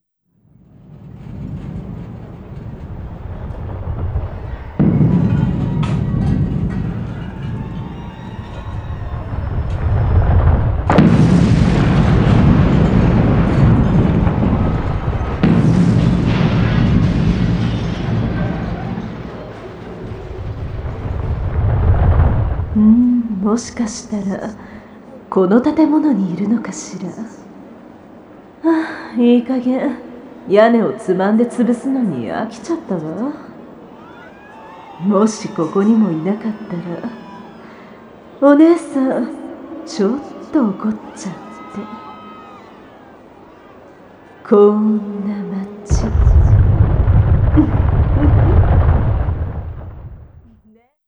地響き系が好きな方に気に入っていただけたらと思います。